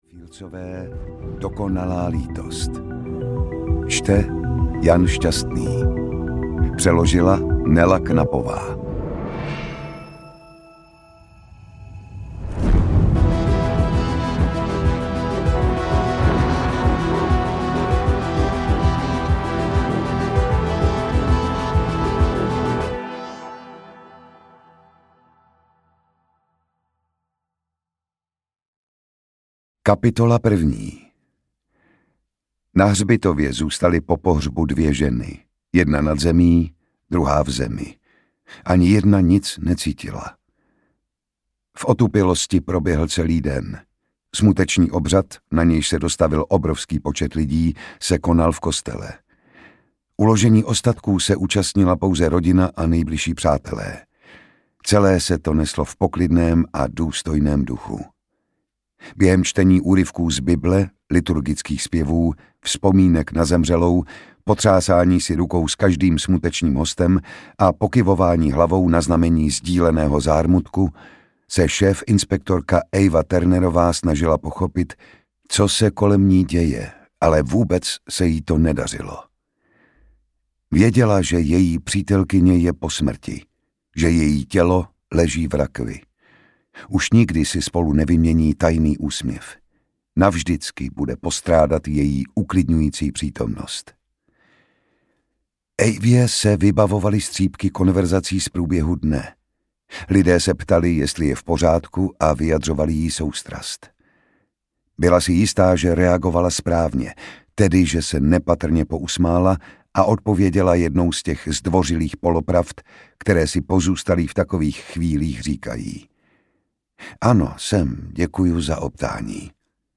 Ukázka z knihy
• InterpretJan Šťastný